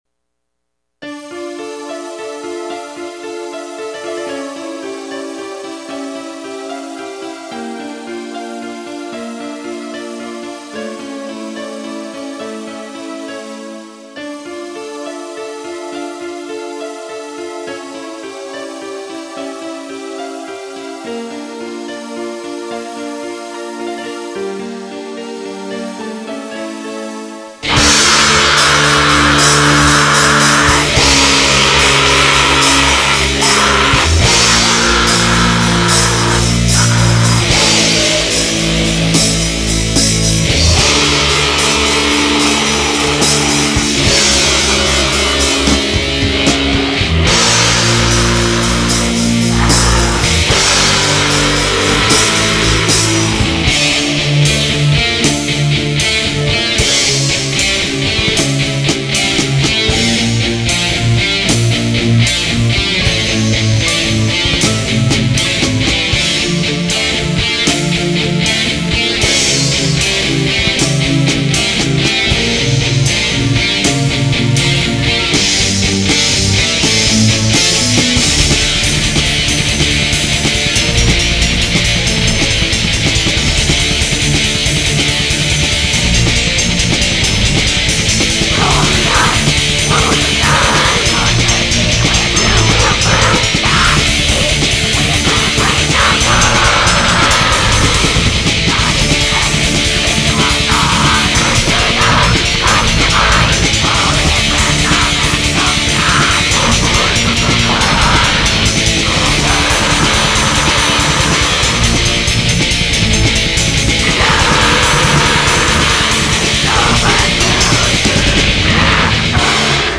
Houston's Premier Metal Band.